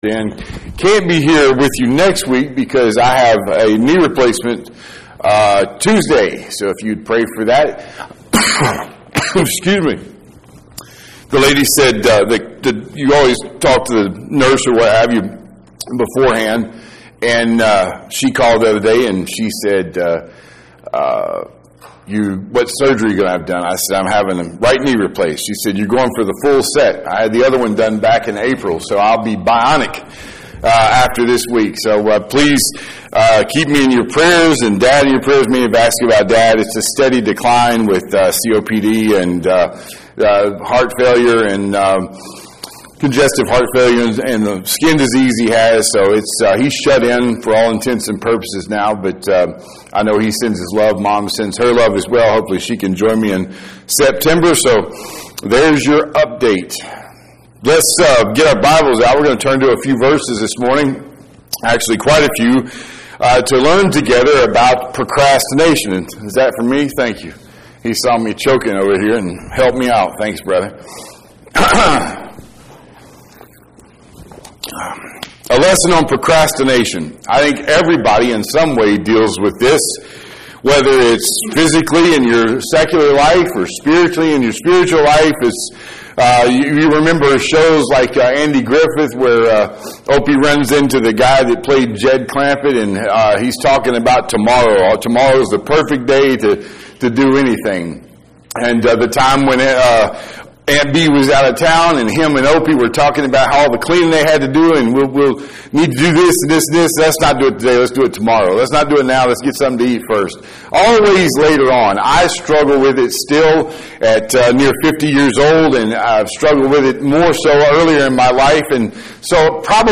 7-13-25 Bible Study – Procrastination